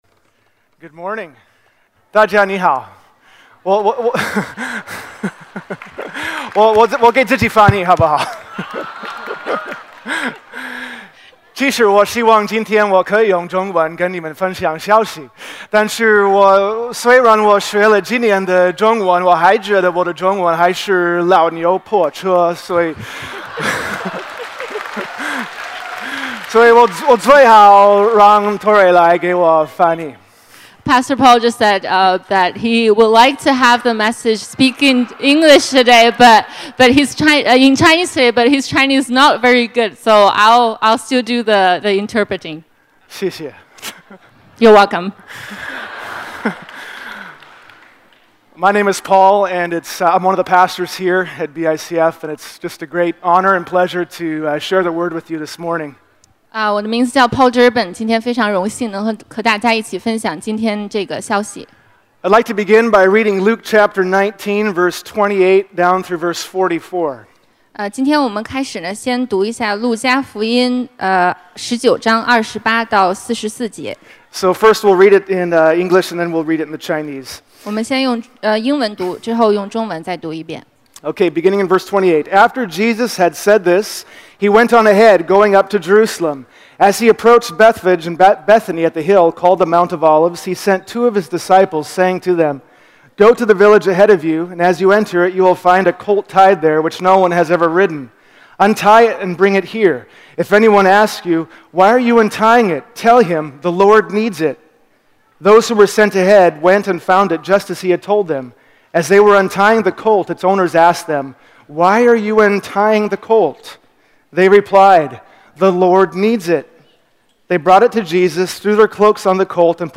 主日证道 |  王来了
IMS Sermons